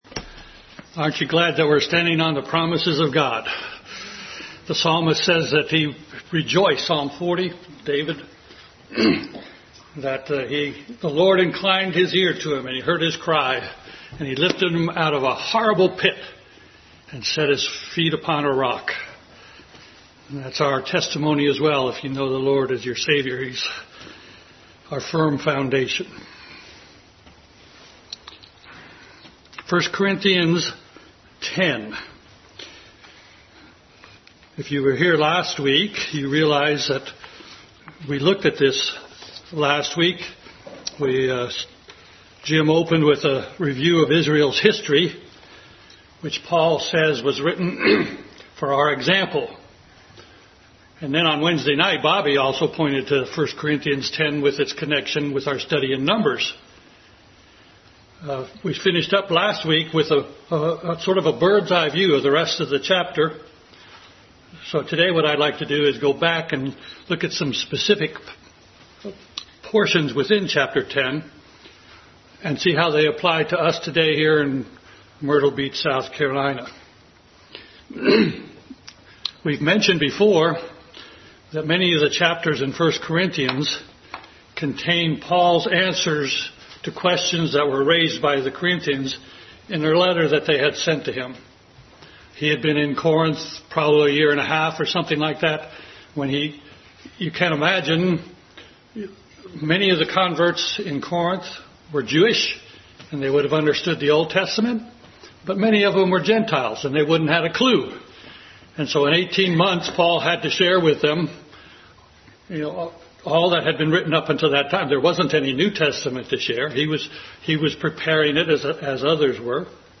Adult Sunday School Class continued study in 1 Corinthians.